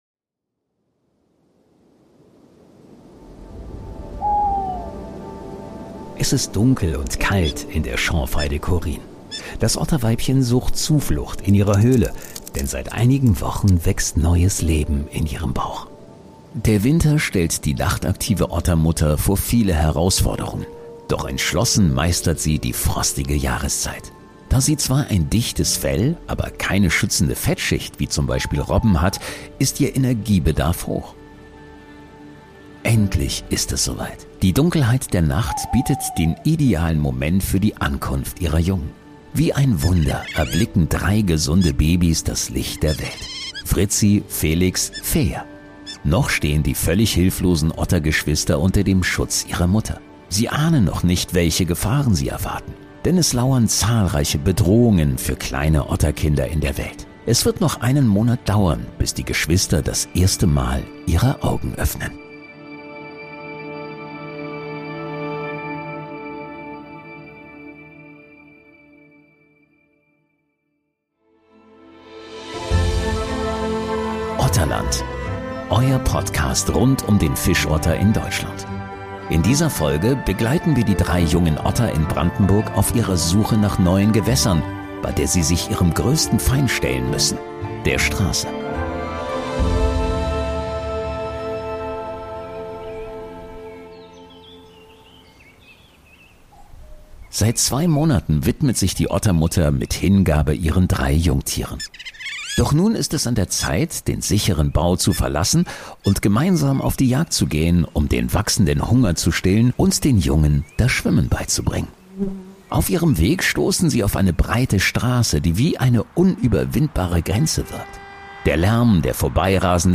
„Otterland“ ist ein Storytelling-Podcast, der eine fiktive Geschichte über eine Otterfamilie quer durch Deutschland erzählt, basierend auf realen Erfahrungen und Fakten zu Fischottern.